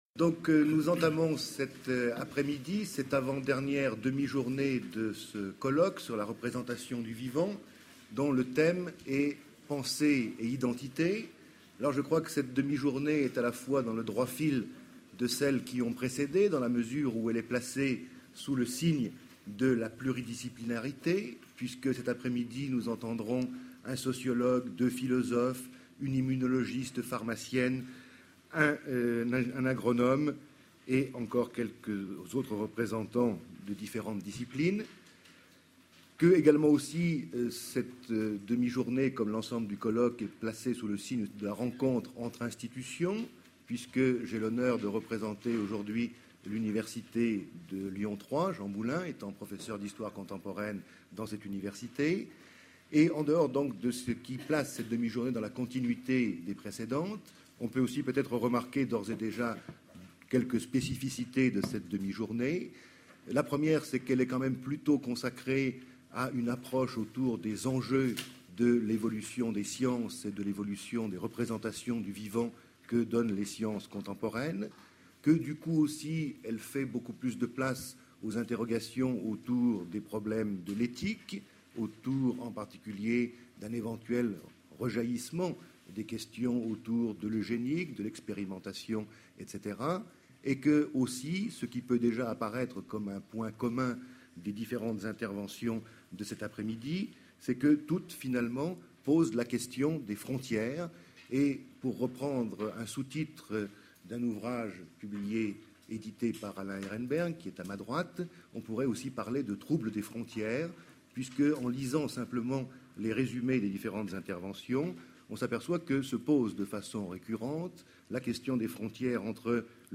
Colloque La représentation du vivant : du cerveau au comportement Session Pensée et identité